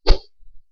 slash.wav